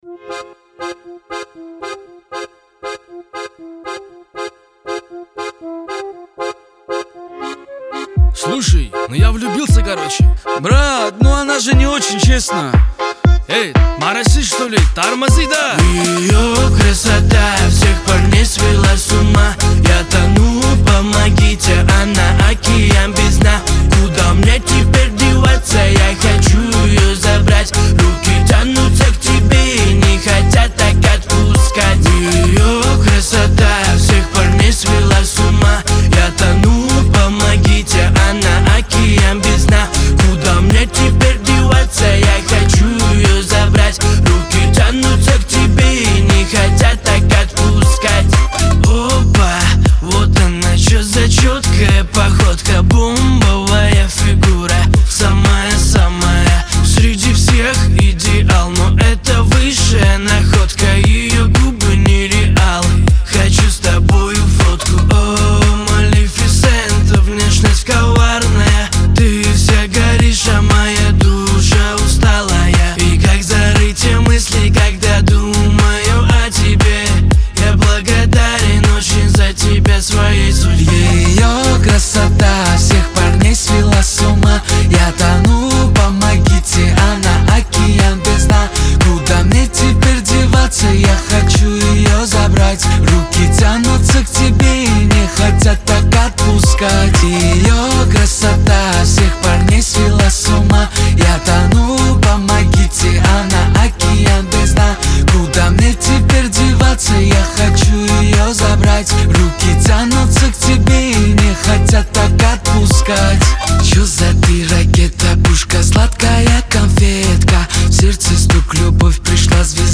Веселая музыка